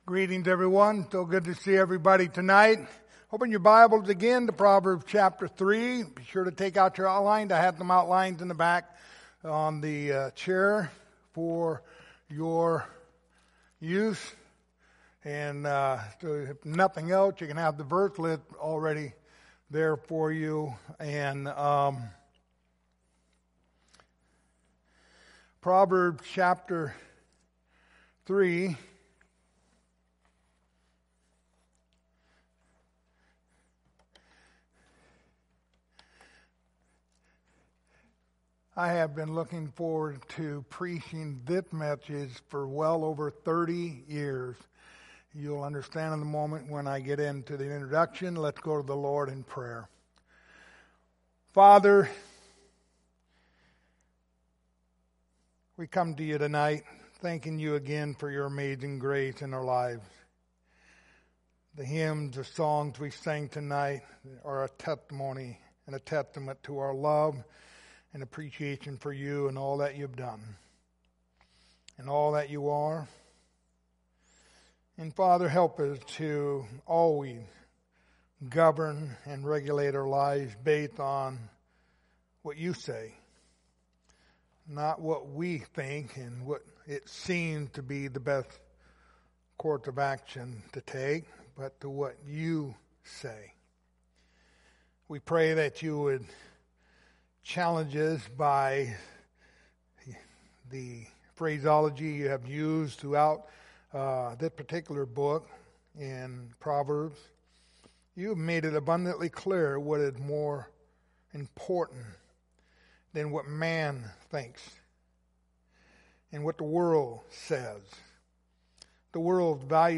The Book of Proverbs Passage: Proverbs 3:14-15 Service Type: Sunday Evening Topics